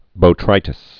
(bō-trītĭs)